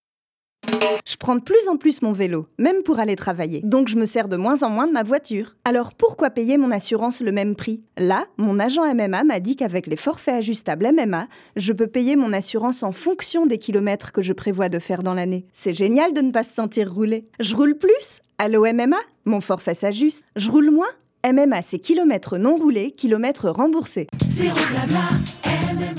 Pub MMA
Voix off
20 - 40 ans - Mezzo-soprano